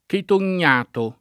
vai all'elenco alfabetico delle voci ingrandisci il carattere 100% rimpicciolisci il carattere stampa invia tramite posta elettronica codividi su Facebook chetognato [ keton’n’ # to ; raro ket 0 n’n’ato ] s. m. (zool.)